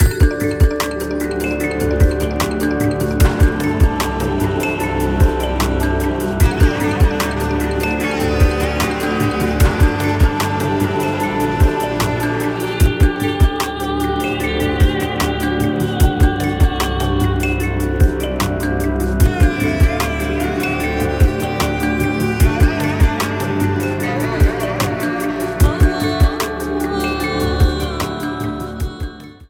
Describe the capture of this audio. Ripped from the game trimmed to 29.5 seconds and faded out the last two seconds